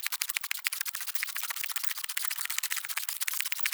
Sfx_creature_trivalve_scuttle_fast_01.ogg